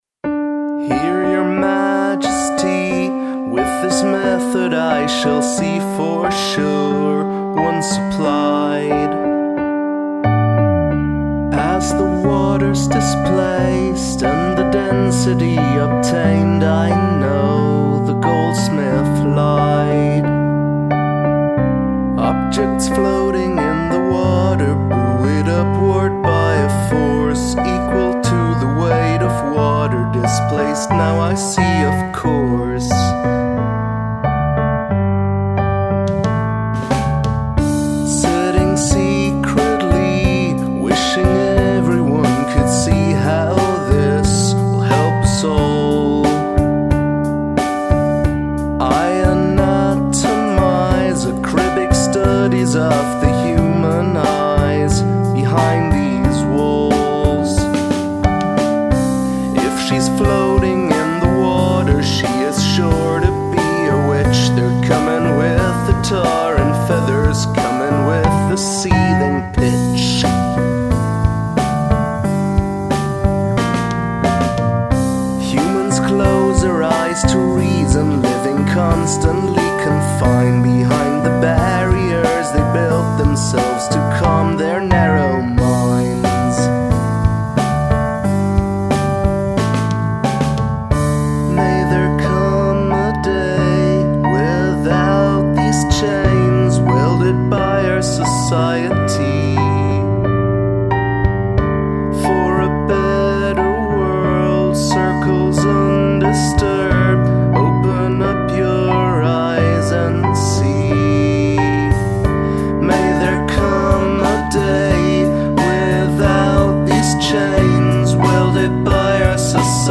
Some classic 20th century songwriting here.